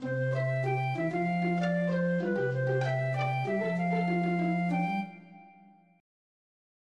MIDI Composition
They are short and experimental.
A clip designed to give the feel of waking up in a nostalgic yesteryear morning of suburban Americana.